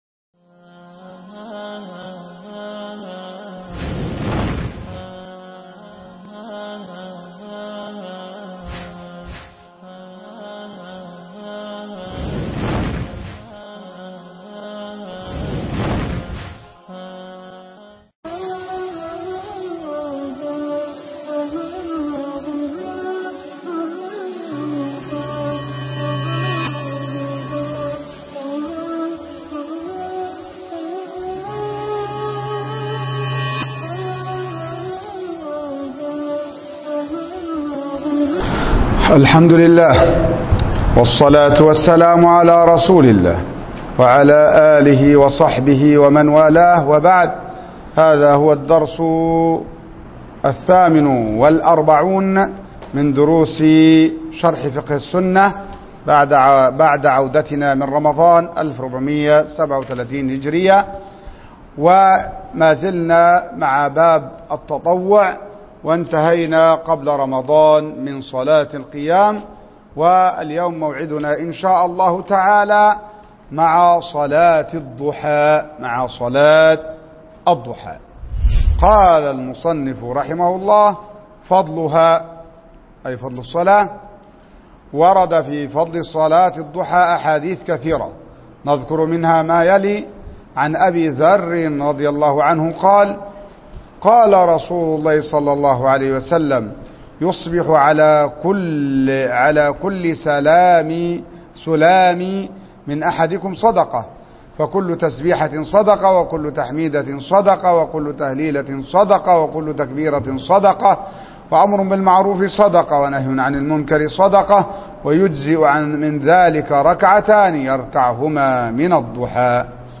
شرح كتاب فقه السنة الدرس 48